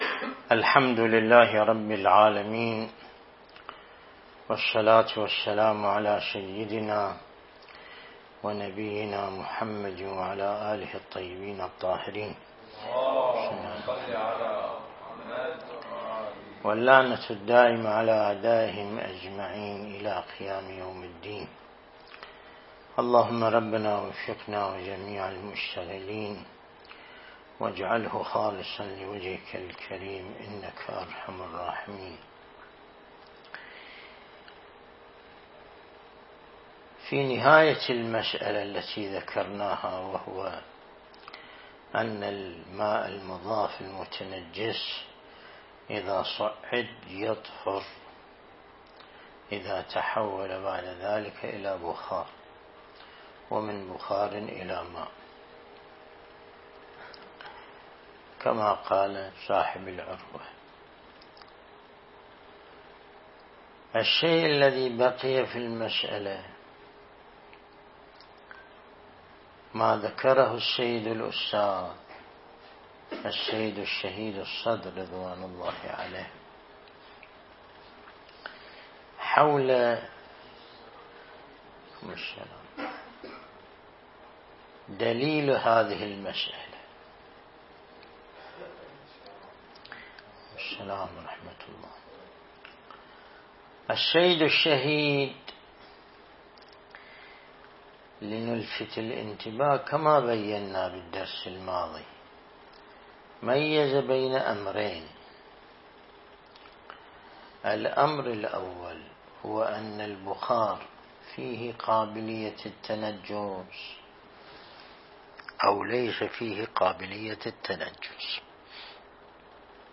الدرس الاستدلالي شرح بحث الطهارة من كتاب العروة الوثقى لسماحة آية الله السيد ياسين الموسوي (دام ظله)